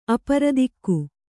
♪ aparadikku